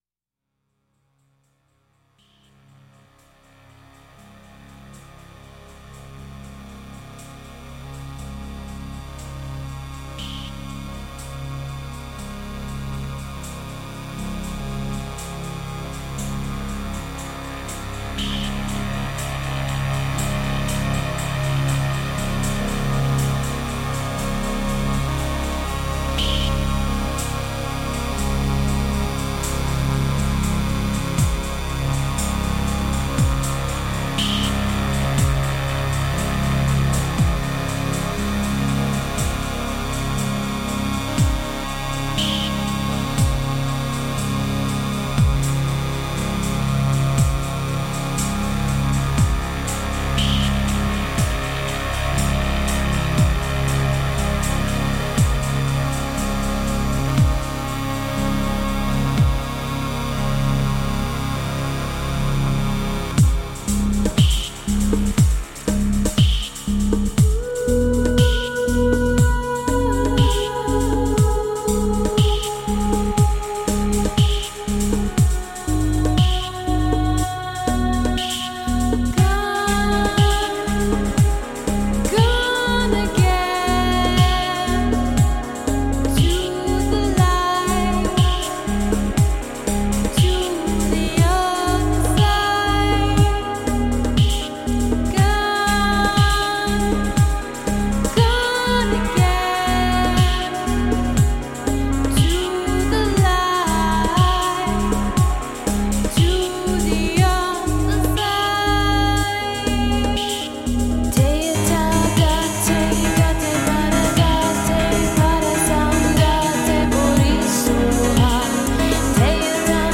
Electronic Music Production (Korg Electribe), Vocals
Recorded at Divasonic Studios in Long Beach, CA